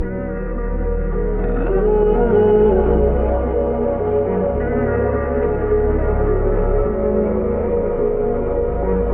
LOOP - CUM OVER.wav